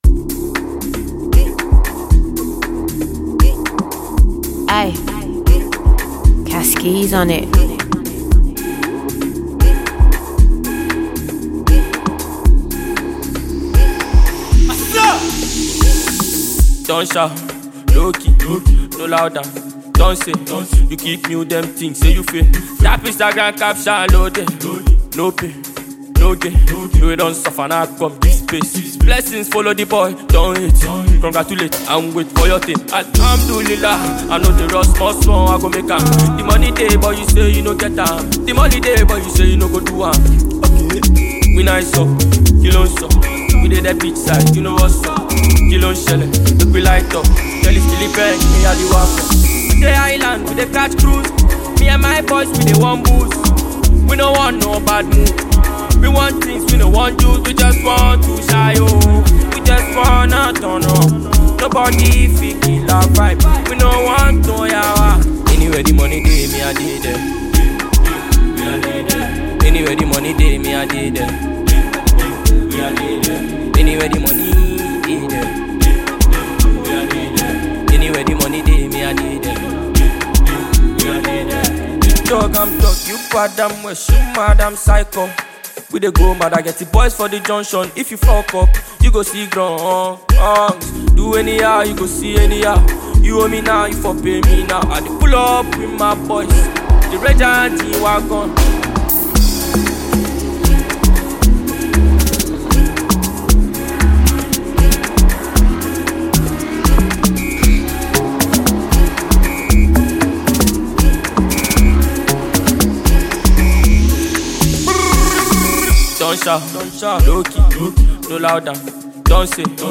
Ghanaian Reggae/Dancehall and Afrobeats artist